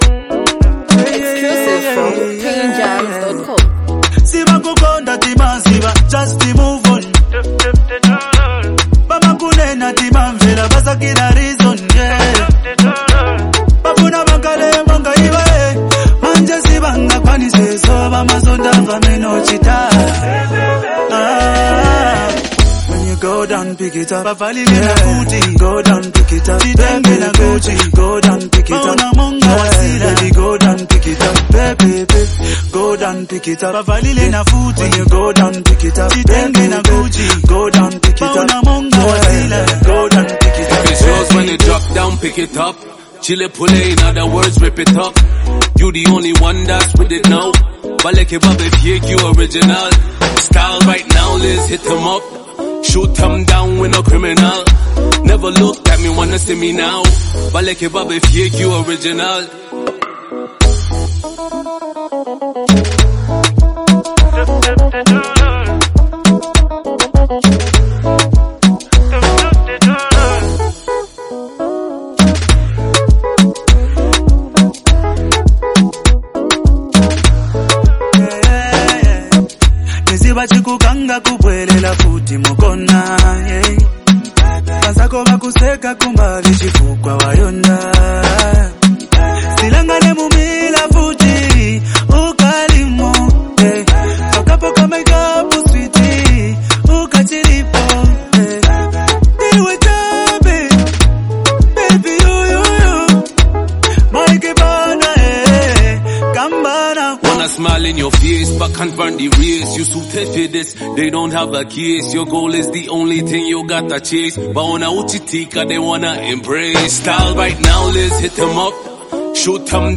energetic and motivational anthem
catchy and uplifting hook